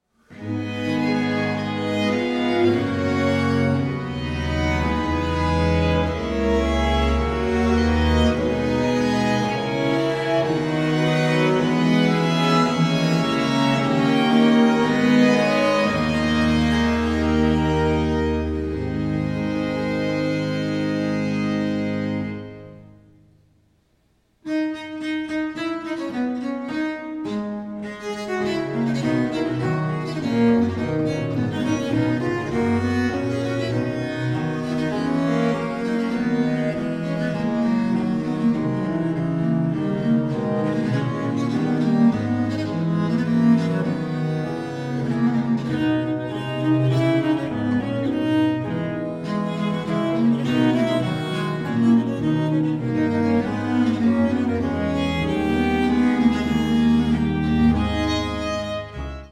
• Genres: Early Music
Baroque string ensemble